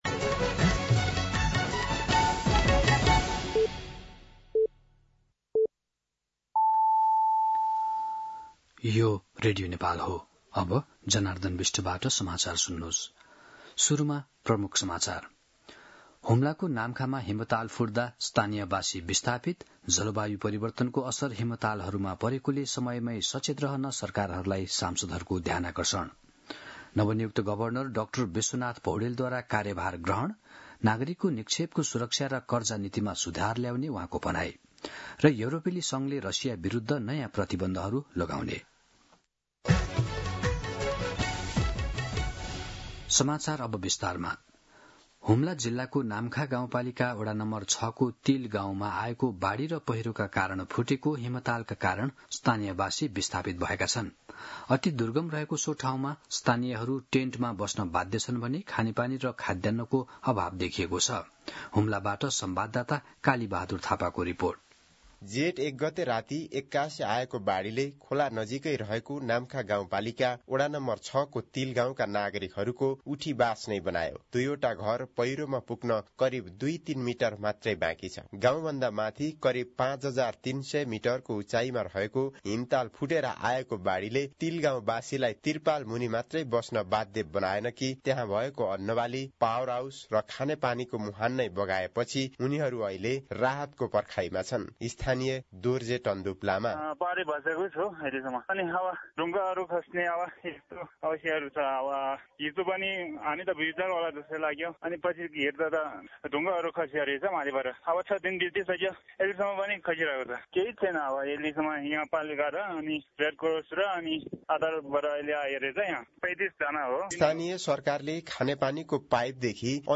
दिउँसो ३ बजेको नेपाली समाचार : ७ जेठ , २०८२
3-pm-Nepali-News-02-07.mp3